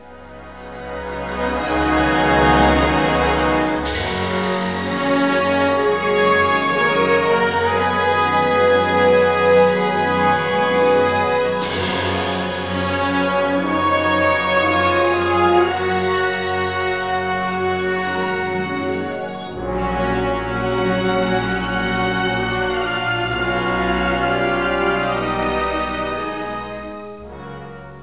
Filmmusik (2/2)